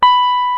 FLYING V 6.wav